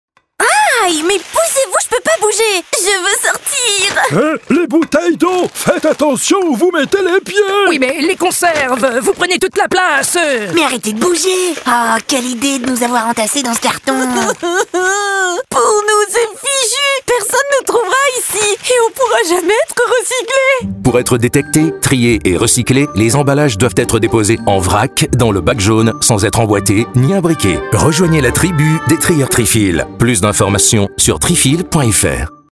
SPOT RADIO Affiche format A3 Affiche format PAYSAGE